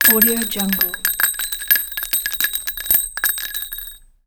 دانلود افکت صدای افتادن قطعه فلزی رو کاشی 8
صدای افتادن فلز روی کاشی 8 یکی از همین الماس‌های صوتیه که با ظرافت کامل ضبط شده و هیچ‌گونه نویز یا افکتی مصنوعی توی اون نیست.
Sample rate 16-Bit Stereo, 44.1 kHz